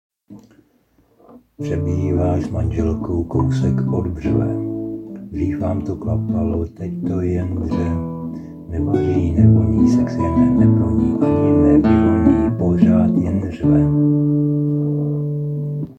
A ještě jsem chtěl vyzdvihnout hudební projev a kytaru.
Škoda, že jsi to nenazpíval celé:-)